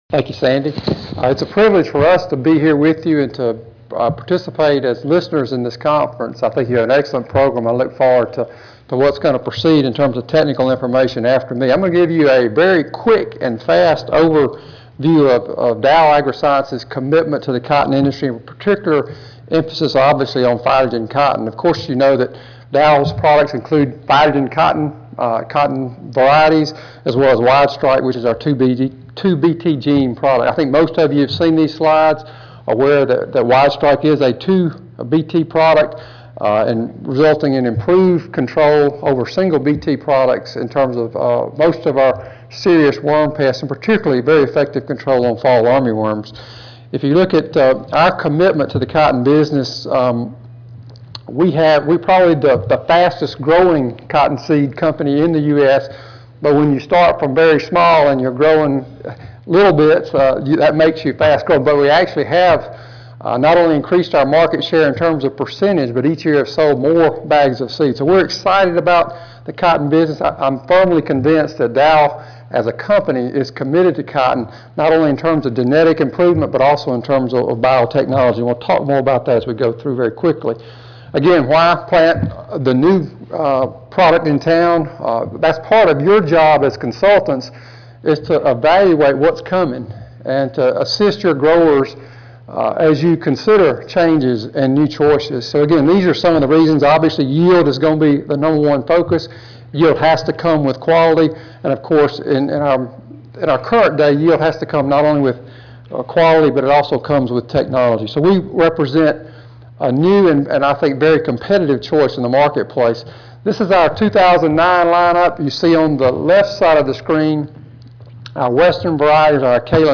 Cotton Consultants Conference
Audio File Recorded presentation